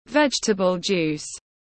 Nước ép rau xanh tiếng anh gọi là vegetable juice, phiên âm tiếng anh đọc là /ˈvedʒ.tə.bəl ˌdʒuːs/
Vegetable juice /ˈvedʒ.tə.bəl ˌdʒuːs/